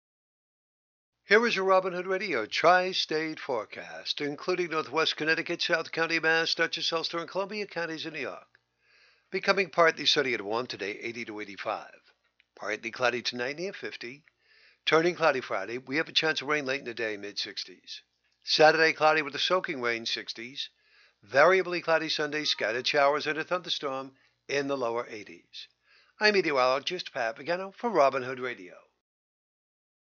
WHDD-THURSDAY-MID-DAY-WEATHER.mp3